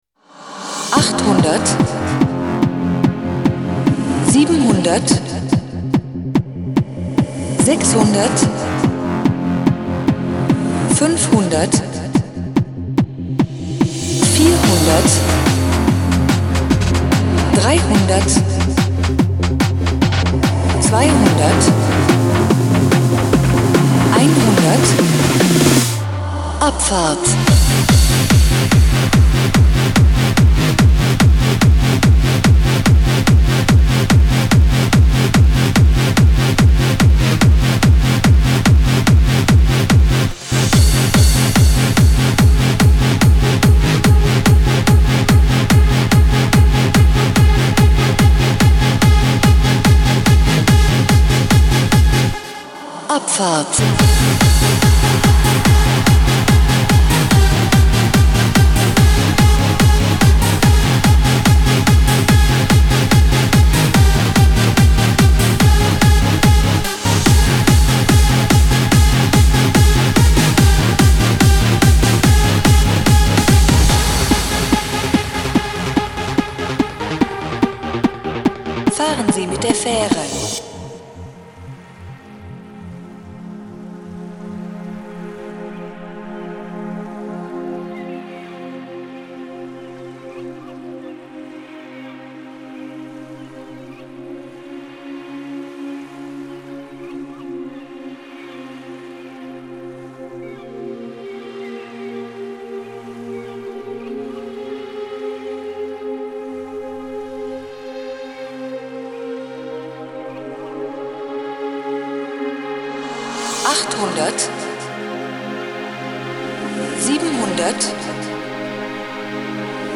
Hard Trance DJ set